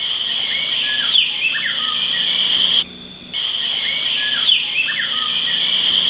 Cucarachero Bigotudo Montano (Pheugopedius mystacalis)
Nombre en inglés: Whiskered Wren
Fase de la vida: Adulto
País: Colombia
Condición: Silvestre
Certeza: Vocalización Grabada